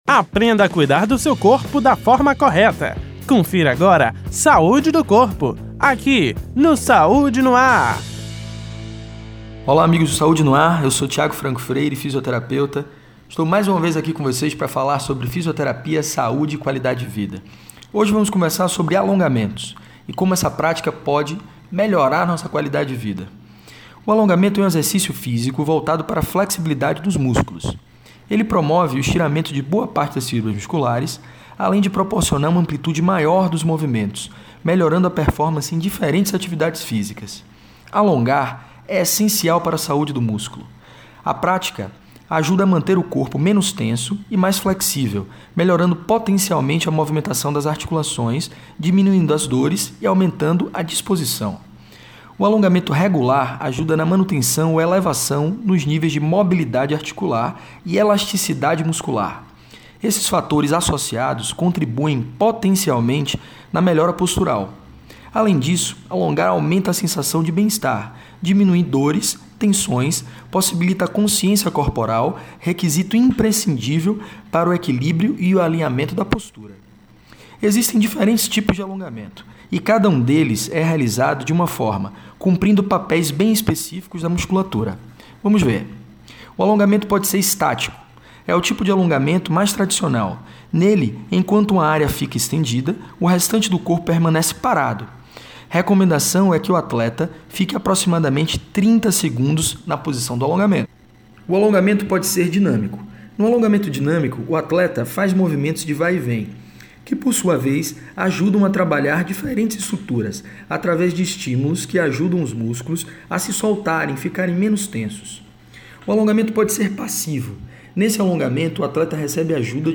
O assunto foi tema do Quadro: Saúde do Corpo exibido toda segunda-feira no Programa Saúde no ar, veiculado pela Rede Excelsior de Comunicação: AM 840, FM 106.01, Recôncavo AM 1460 e Rádio Saúde no ar / Web.